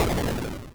Enemy_Crash.wav